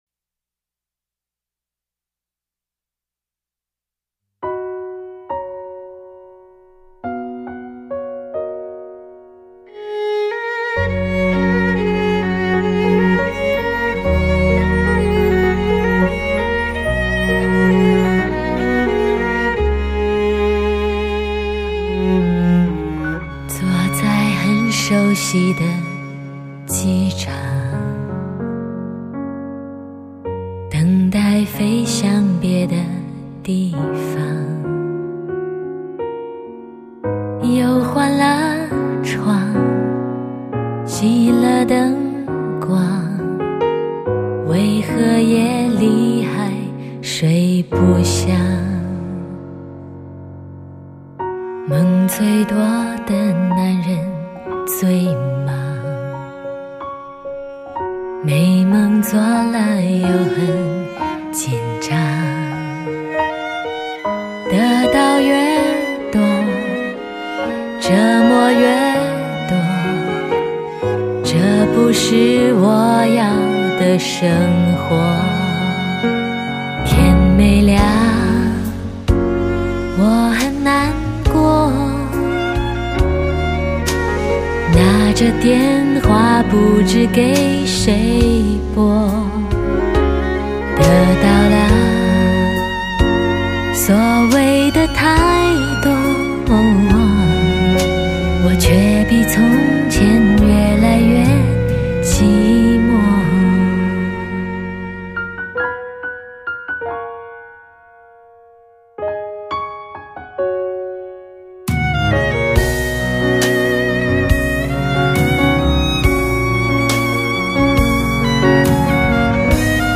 音乐类型: Pop/流行